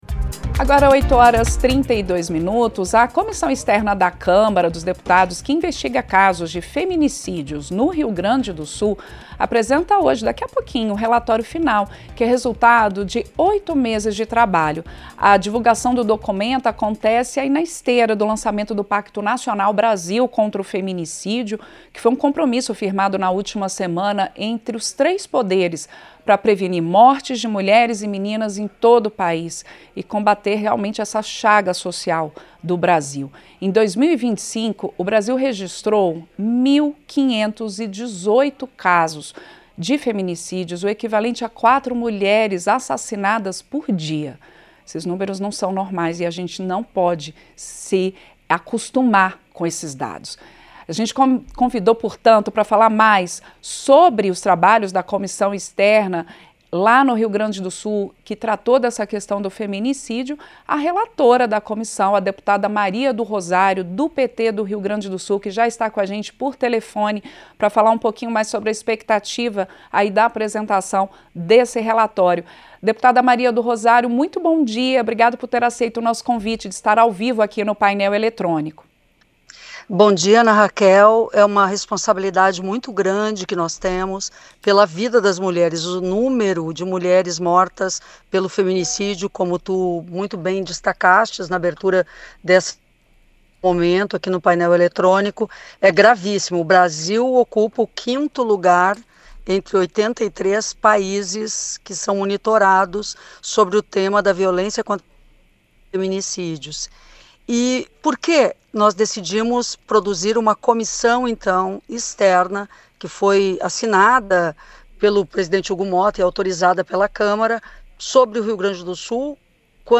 Em entrevista ao Painel Eletrônico, nesta terça-feira, a deputada Maria do Rosário (PT-RS) explicou que, embora o grupo tenha se debruçado sobre casos no Rio Grande do Sul, os crimes contra mulheres são uma realidade nacional, que precisa tanto de punição firme quanto de mudança cultural.
Entrevista - Dep. Maria do Rosário (PT-RS)